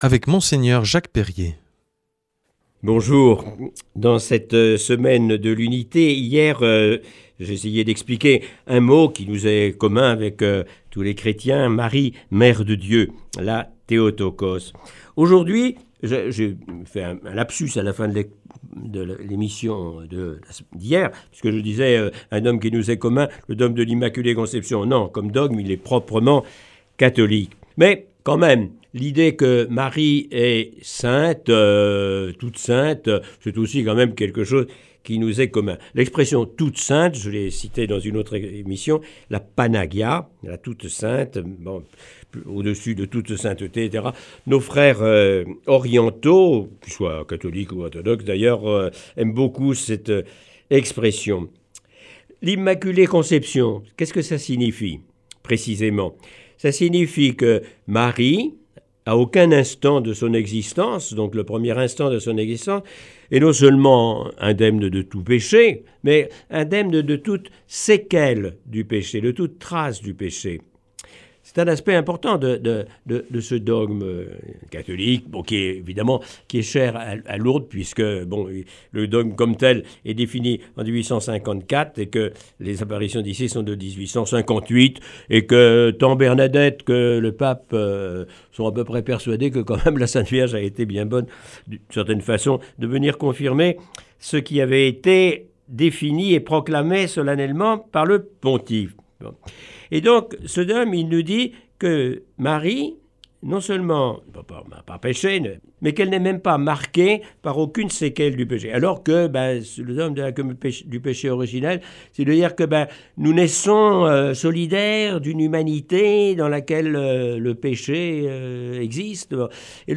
Enseignement Marial du 24 janv.
Aujourd’hui avec Mgr Jacques Perrier.